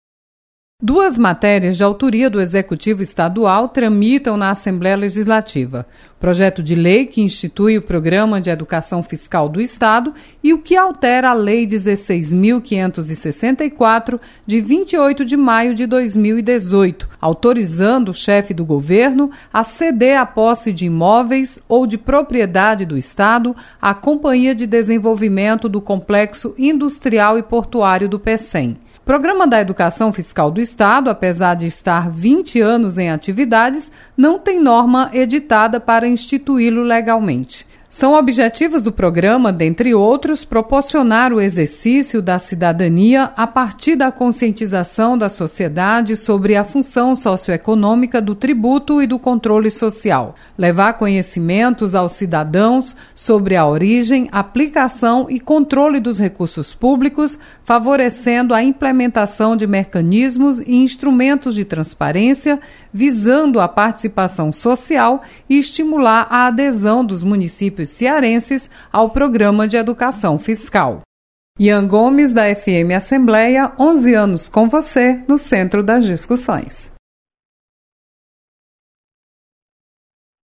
Governo Propõe instituição do Programa Educação Fiscal. Repórter